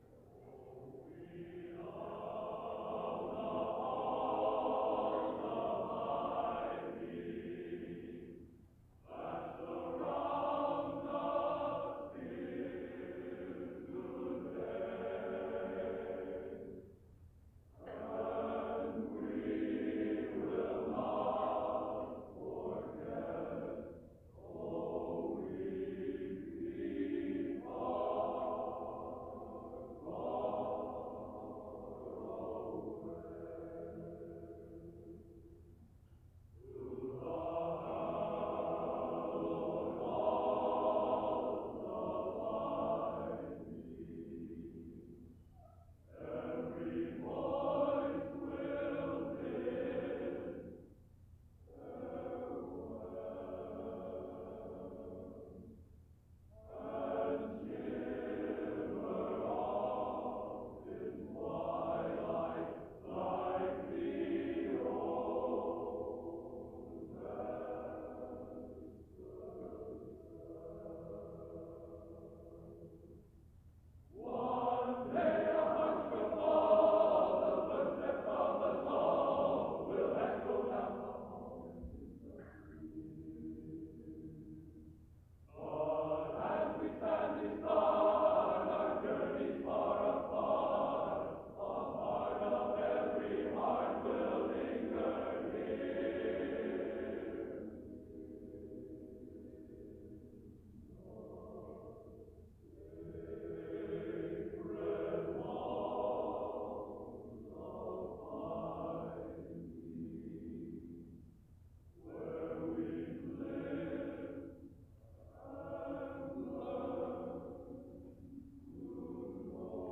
[applause and run-on]
Genre: A Cappella Collegiate | Type: End of Season